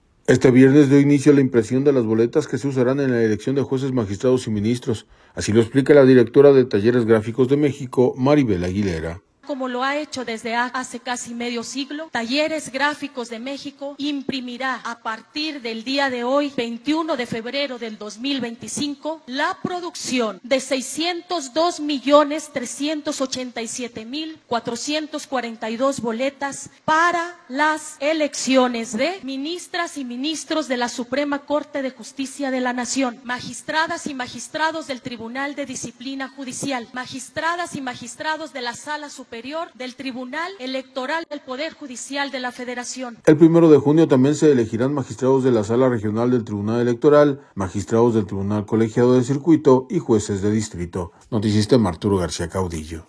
Este viernes dio inicio la impresión de las boletas que se usarán en la elección de jueces, magistrados y ministros, así lo explica la directora de Talleres Gráficos de México, Maribel Aguilera.